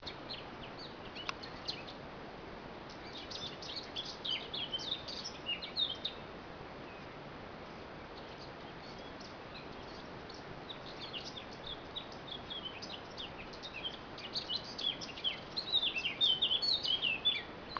Non so identificare questo canto.
Mi sembra il canto di una Capinera
Il canto diventa pił tipico soprattutto nell'ultima parte della registrazione.